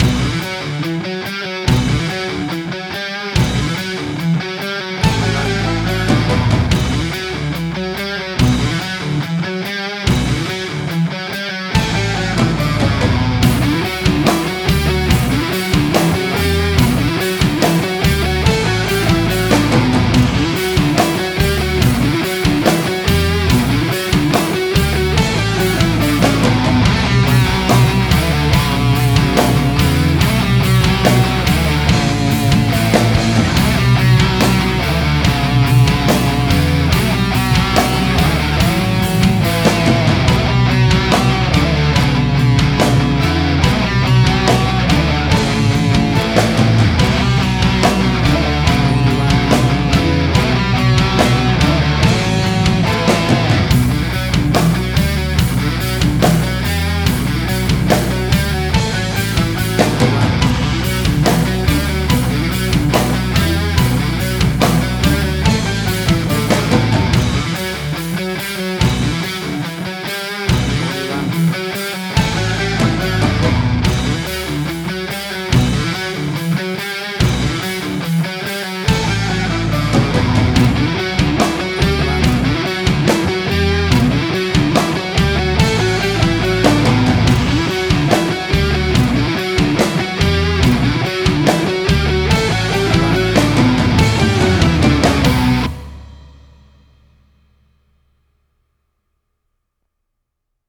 Hard Rock
Heavy Metal. Future Retro Wave
Tempo (BPM): 72